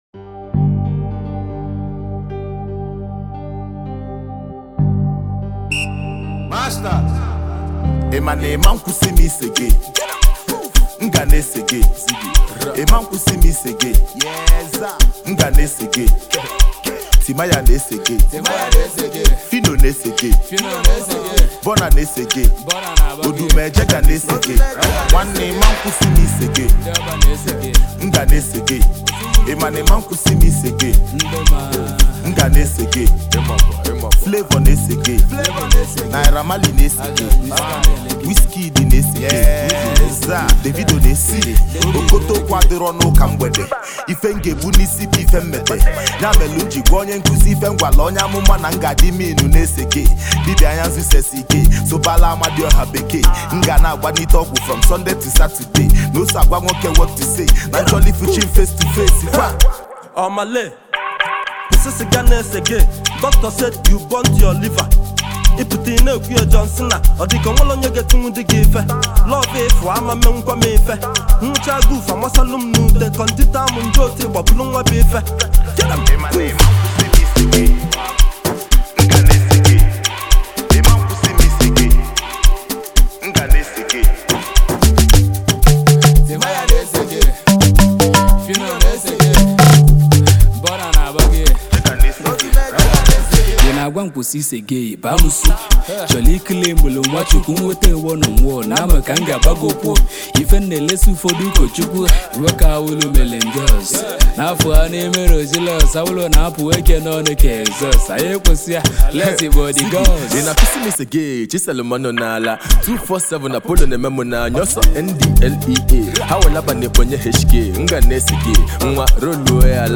This is a street jam that you can vibe to all day.